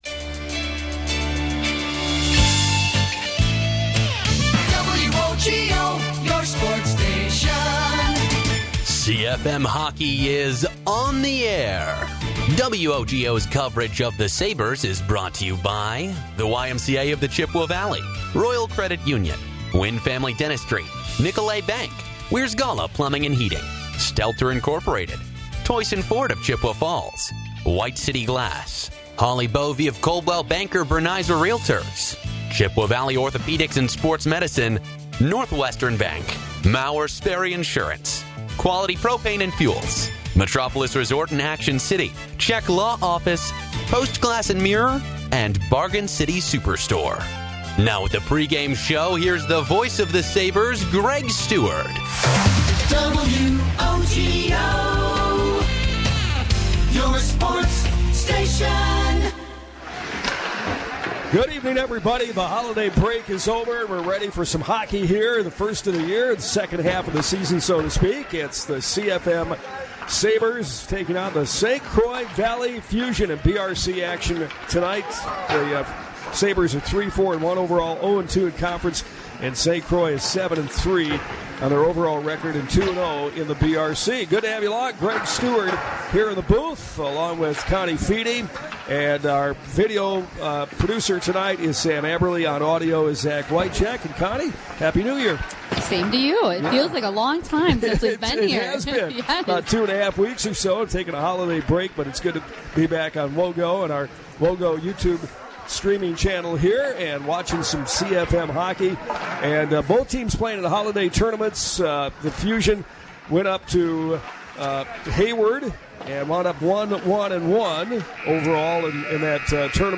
Share to X Share to Facebook Share to Pinterest Labels: High School Sports